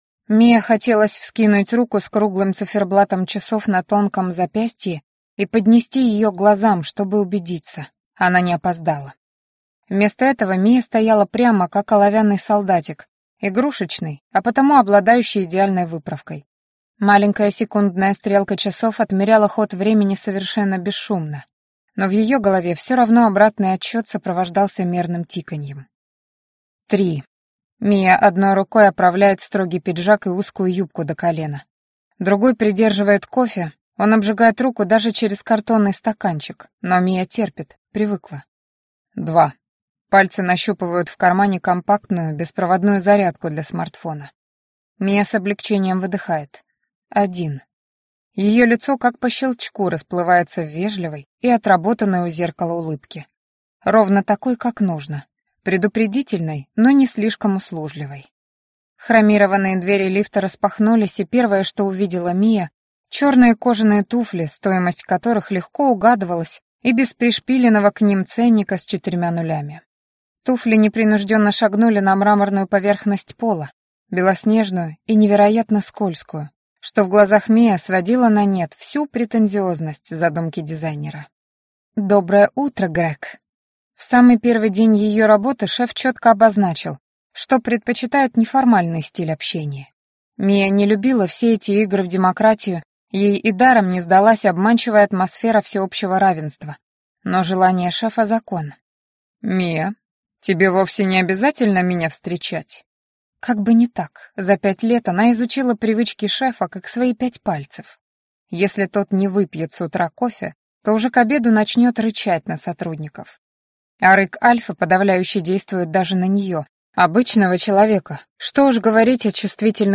Аудиокнига Наперекор инстинкту | Библиотека аудиокниг